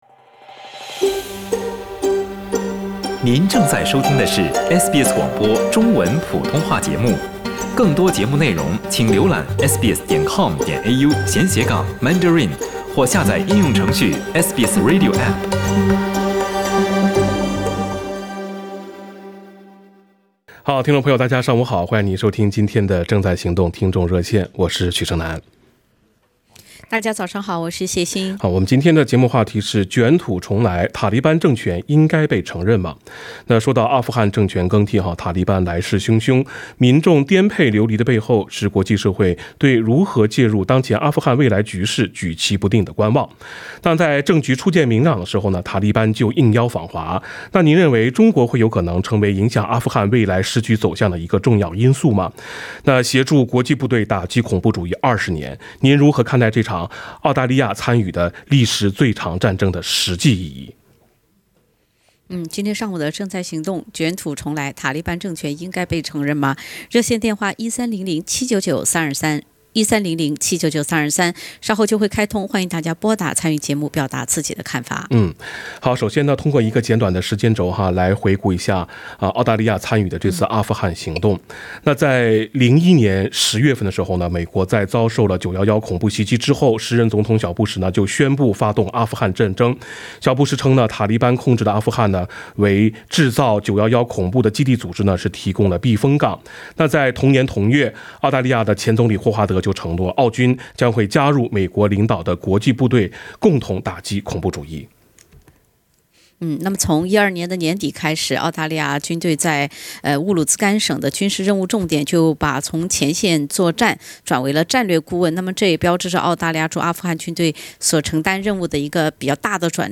（点击封面图片，收听热线回放）